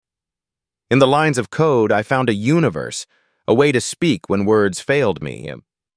🎤 Emotional Quote TTS
tts_aeeb6e3ebf.mp3